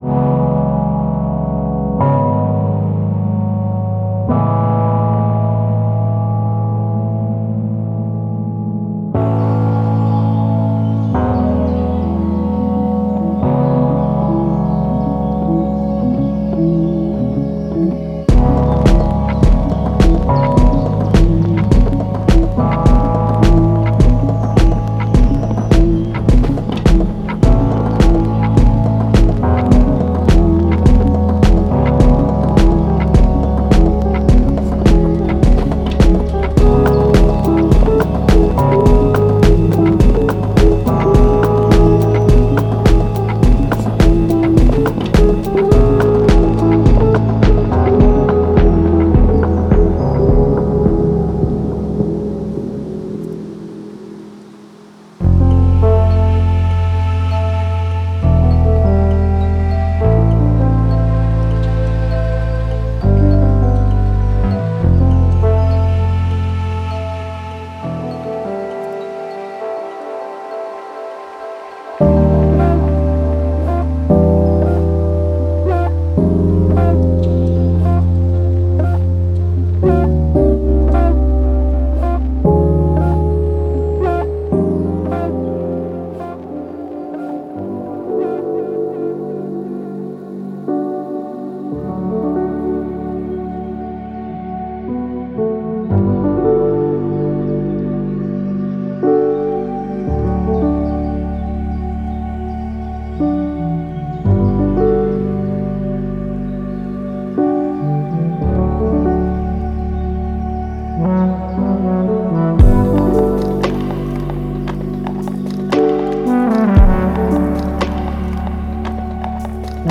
这是一个宁静的声音环境，充满清澈的旋律和繁星点点的和弦进行。
• 121响亮的鼓声
• 20个精确的鼓循环（全弹和干弹）
• 10个自然低音循环（包括Midi文件）
• 10个丰富的和弦循环（包括Midi文件）
• 35个平滑的旋律循环（包括Midi文件）
• 节奏-100、105、115BPM